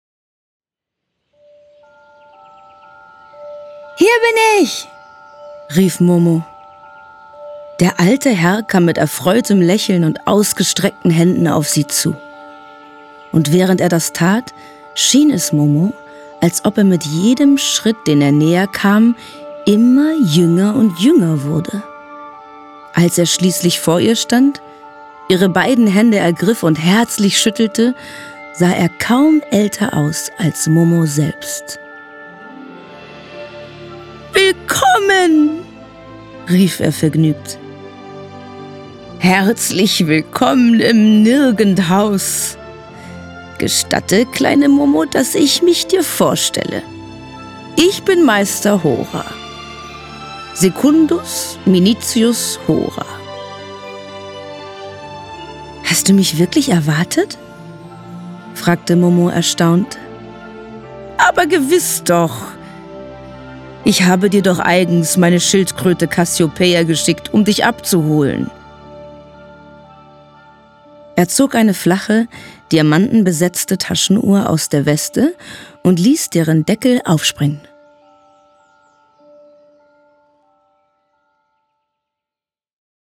dunkel, sonor, souverän, markant
Mittel minus (25-45)
Norddeutsch
Audio Drama (Hörspiel)